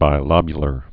(bī-lŏbyə-lər, -lōbyə-)